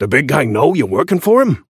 Shopkeeper voice line - The big guy know you're workin' for him?